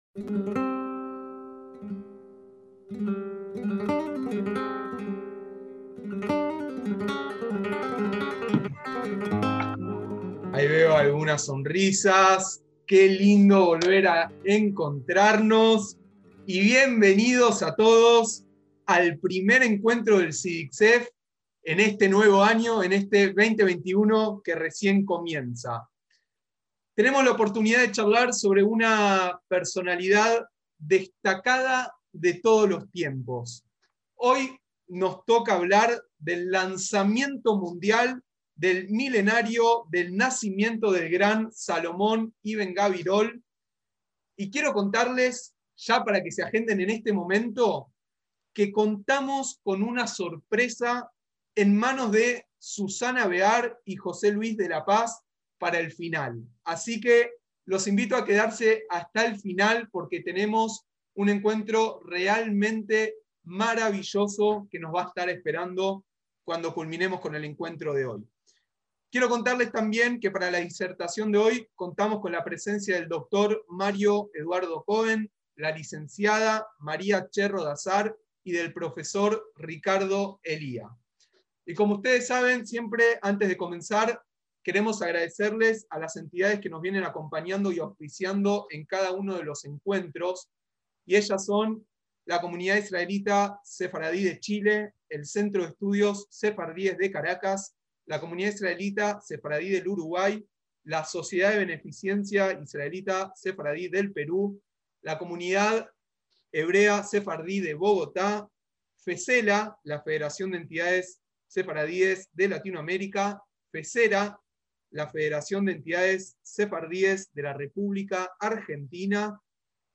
presentan a través de esta mesa telemática la destacada figura del filósofo y poeta Salomón Ibn Gabirol, en el inicio del año en que se cumplen mil del nacimiento en Málaga de esta luminaria sefardí.